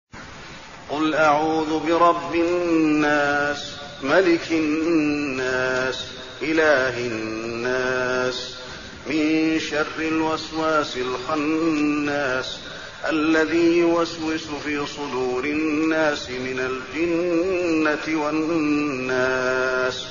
المكان: المسجد النبوي الناس